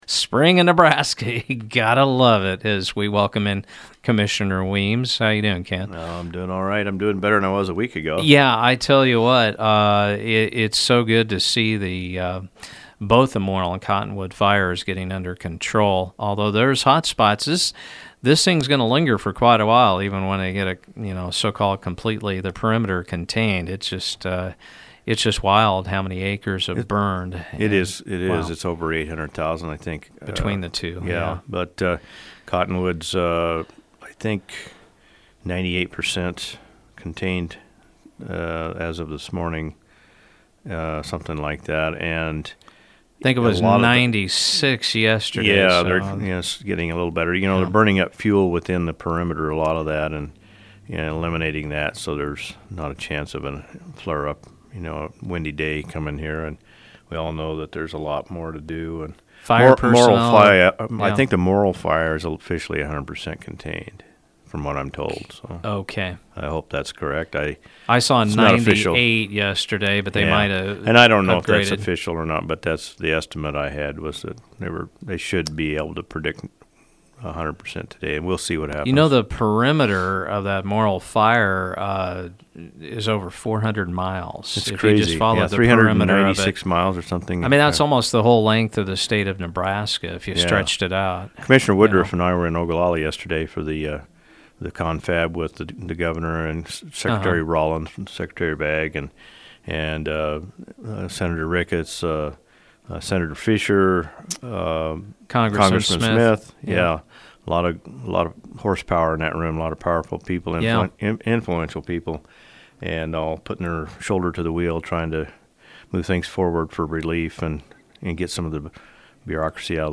Lincoln Co Commissioner Chairman Kent Weems came on Mugs Tuesday to commend firefighters and discuss agenda items the board dealt with at their Monday meeting: